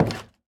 Minecraft Version Minecraft Version 1.21.5 Latest Release | Latest Snapshot 1.21.5 / assets / minecraft / sounds / block / cherrywood_door / toggle4.ogg Compare With Compare With Latest Release | Latest Snapshot